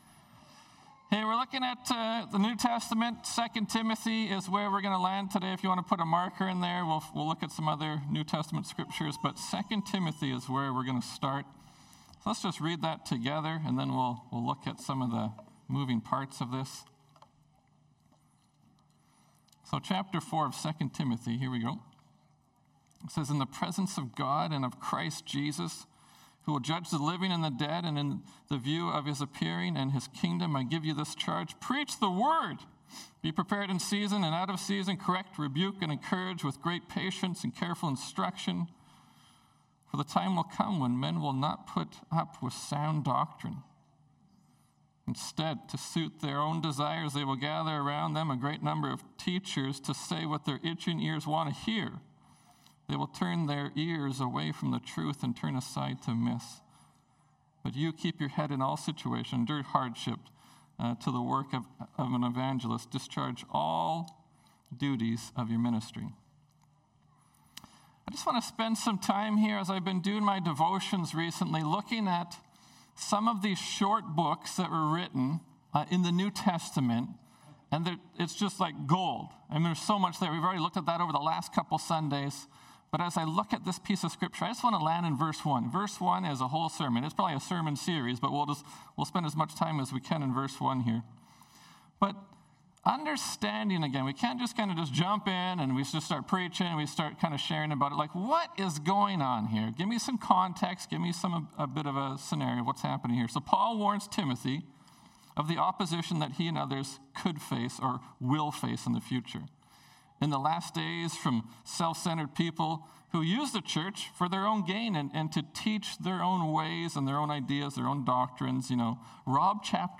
Sermons | Terrace Pentecostal Assembly